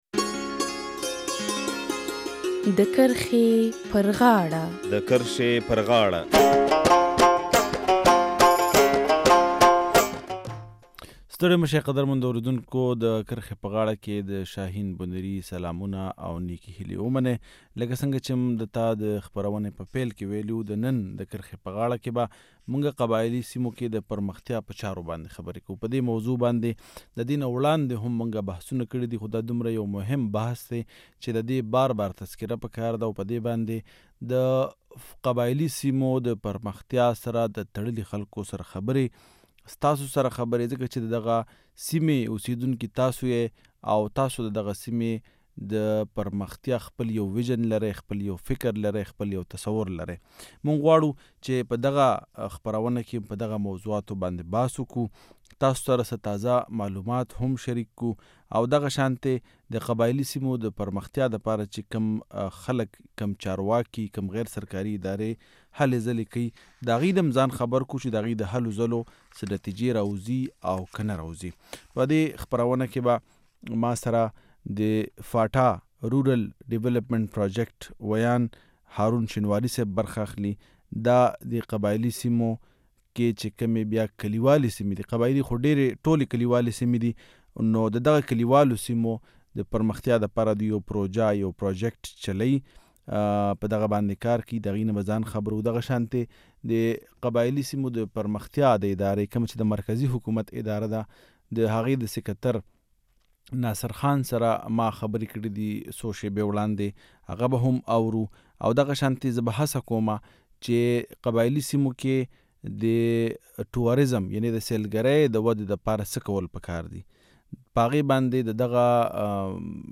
د فاټا ډیو یلیپمنټ اتهارټی به د مرکزی حکومت له خوا منظور شوی د کا لنی پر مختیایی منصوبې د لاندې د قباییلی سیمو د پرمختیا په چارو ۱۵ اربه روپۍ خرڅوی. د نن په خپرونّه کی په دغه موضوع د پرمختیا د چارو د پوهانو او قباییلی خلکو سره خبرې کوو.